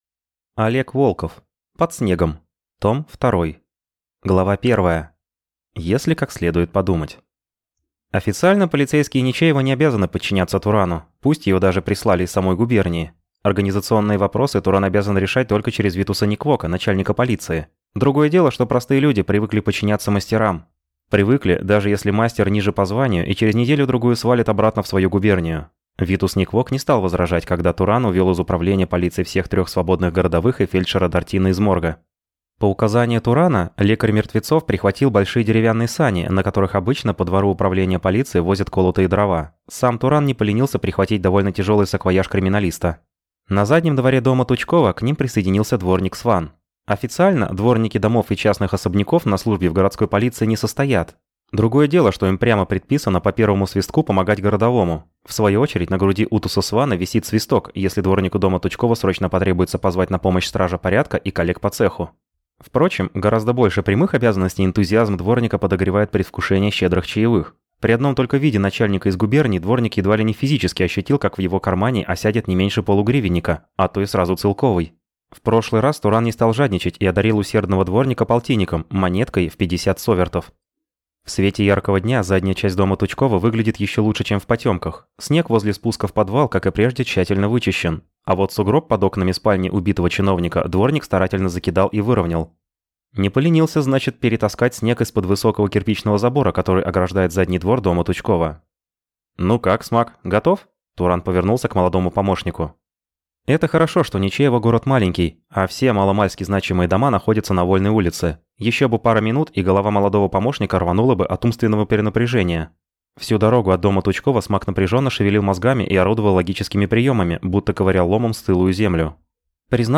Аудиокнига Под снегом. Том II | Библиотека аудиокниг